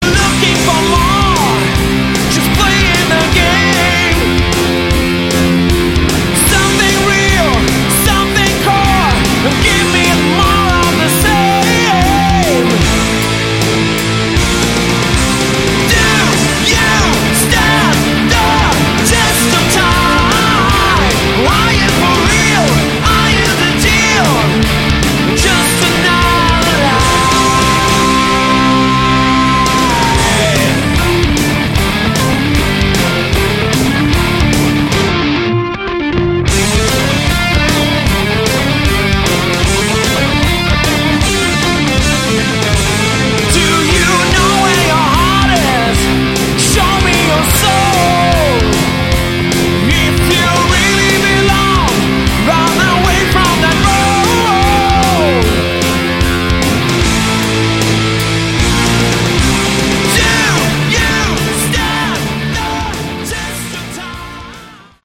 Category: Hard Rock
Guitars
Keyboards
Bass
Drums
Vocals